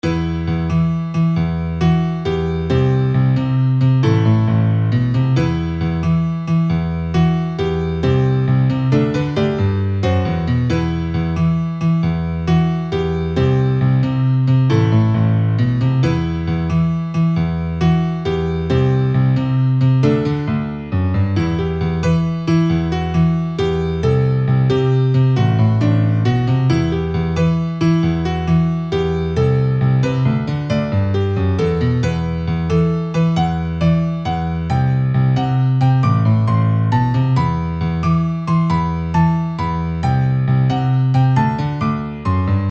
Finally, a basic computer-played piano arrangement
sounds like this, but it is quite flat in terms of dynamics so I'm not sure it's very helpful.
I'm heavily leaning towards 3/2 at 67.5bpm per half note, especially because I think the piece is a lot slower than I would expect 135bpm to sound.